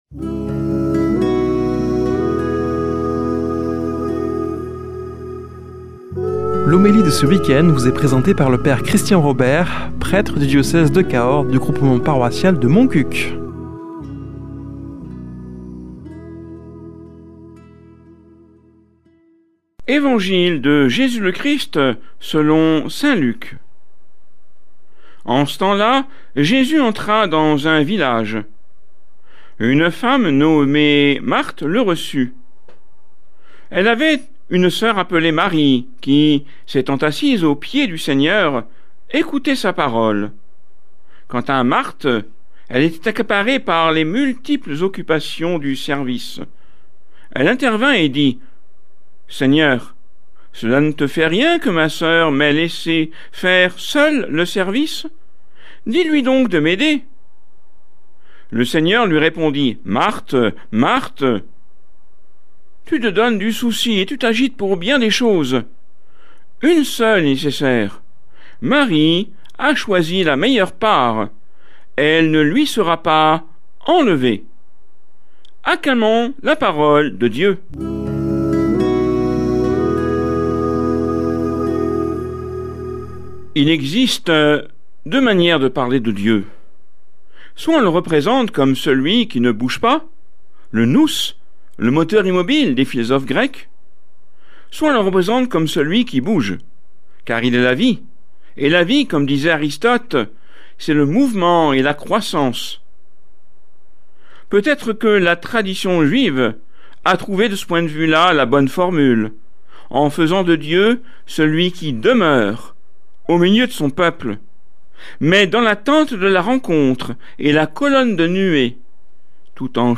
Homélie du 19 juil.